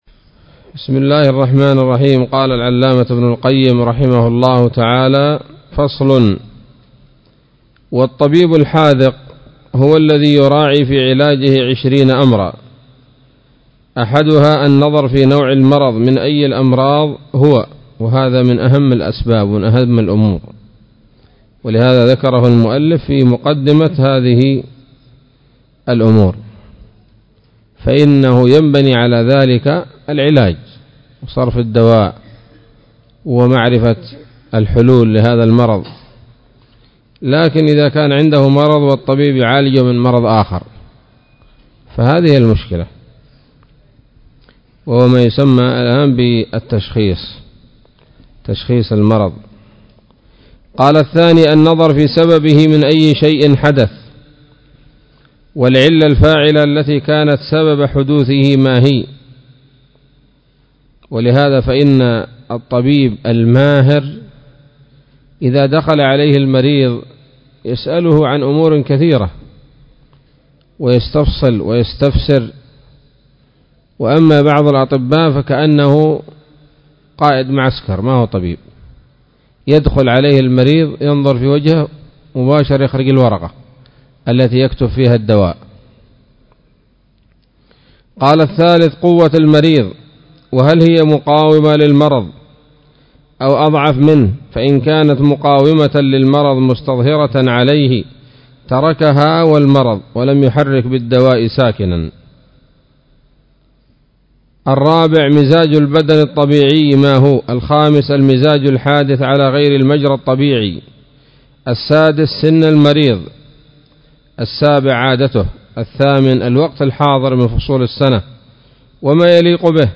الدرس التاسع والثلاثون من كتاب الطب النبوي لابن القيم